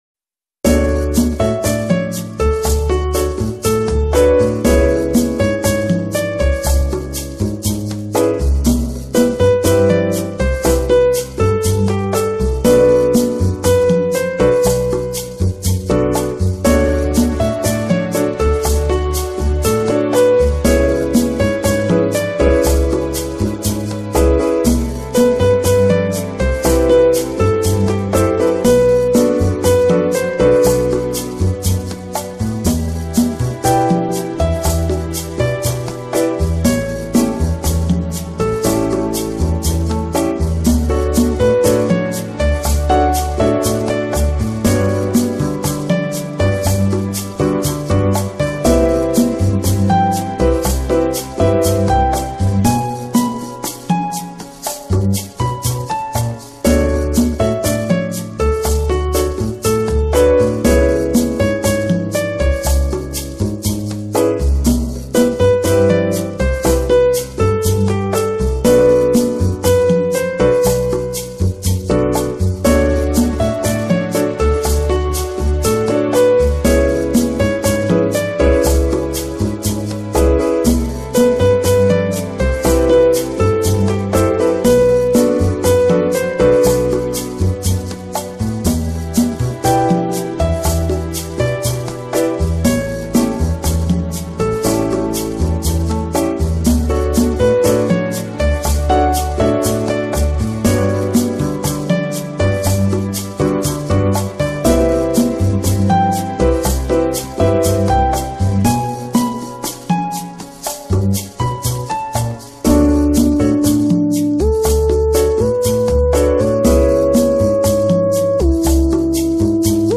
جلوه های صوتی
کیفیت بالا